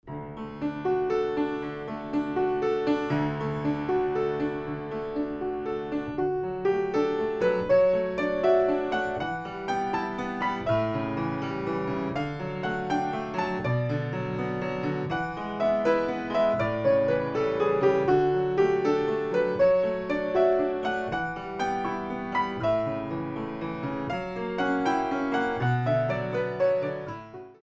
Traditional Compositions for Ballet Class
Piano